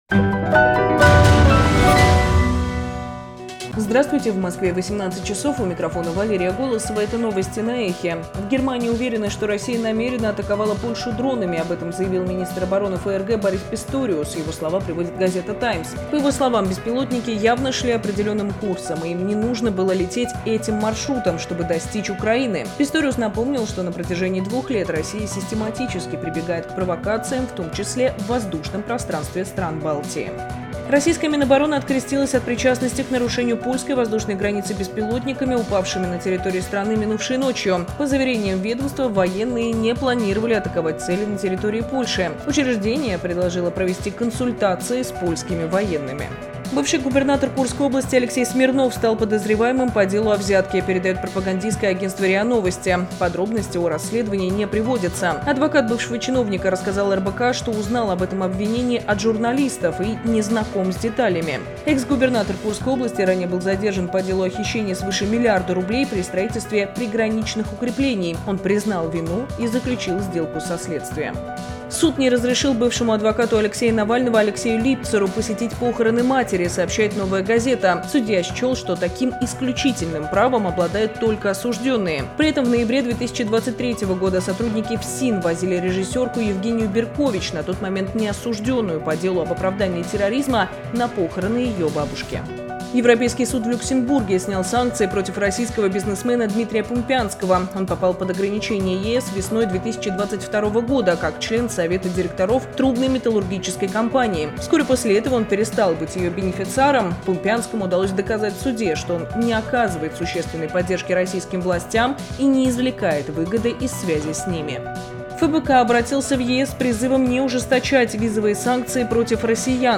Новости 18:00